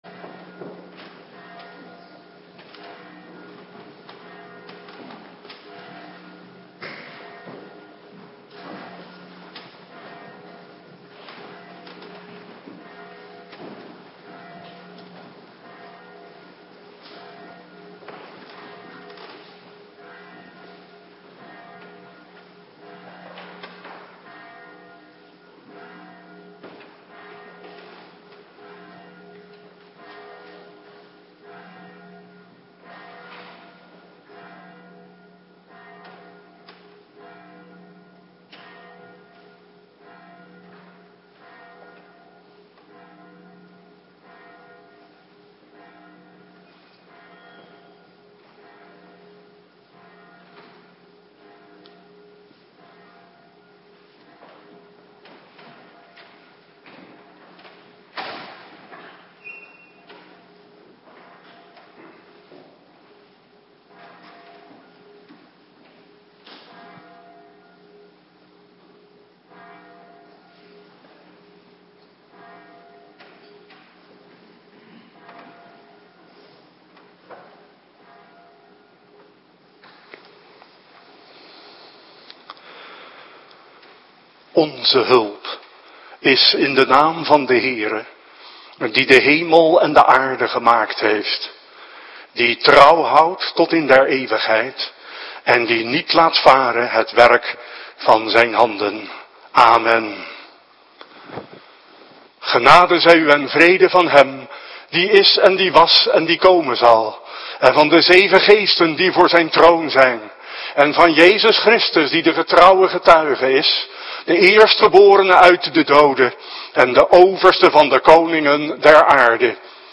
Avonddienst Voorbereiding Heilig Avondmaal